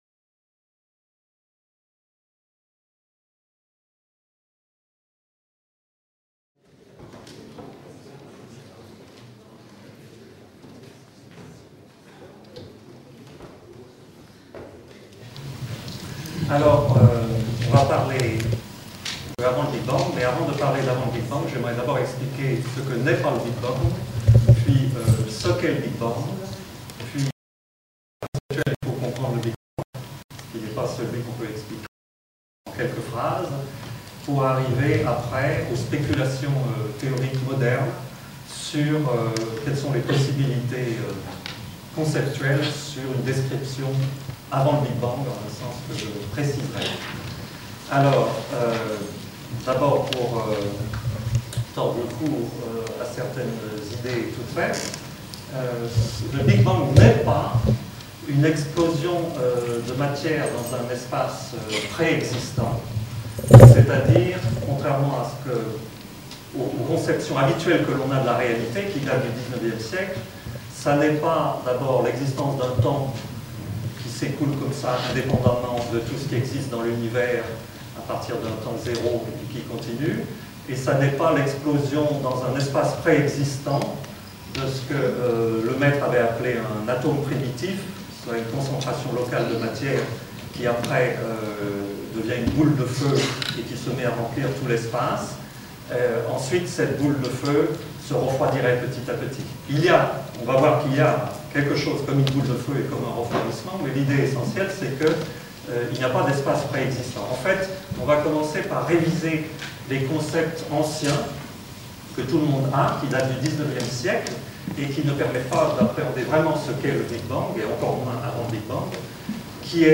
Conférence donnée par Thibaut Damour, dans le cadre des conférences publiques de l'IAP. La qualité de l'image et du son de cette captation vidéo n'est pas aussi bonne qu'elle pourrait l'être si elle était réalisée aujourd'hui, mais l'intérêt qu'elle présente pour l'histoire de la diffusion des connaissances mérite sa présentation ici, à titre d'archive.